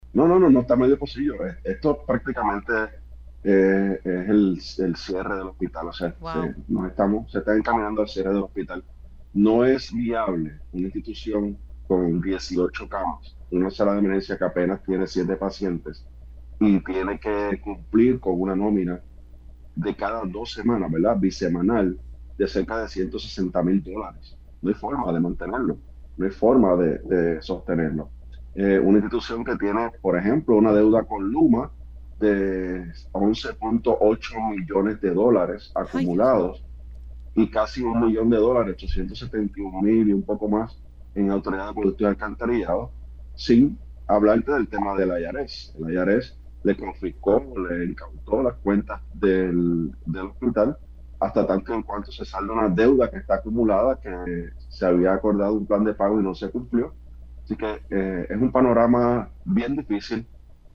No hay forma de sostenerlo“, detalló el legislador en Pega’os en la Mañana.